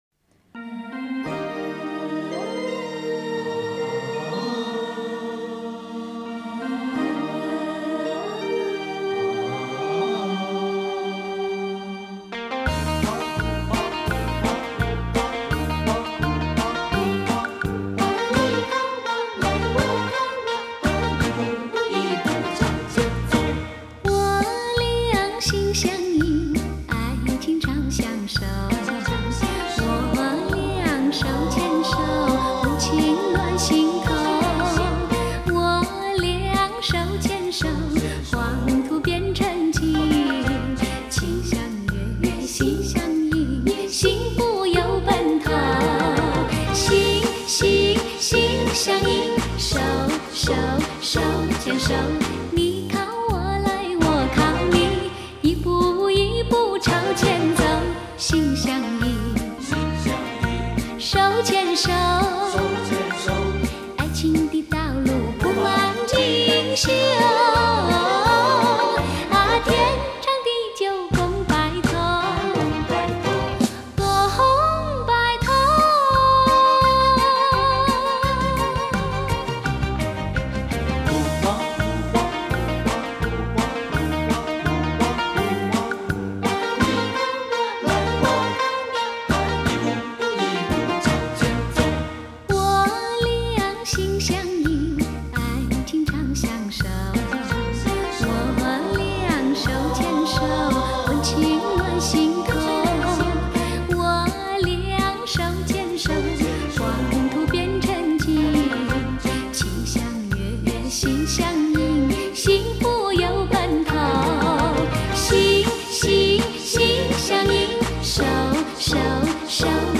Жанр: Chinese pop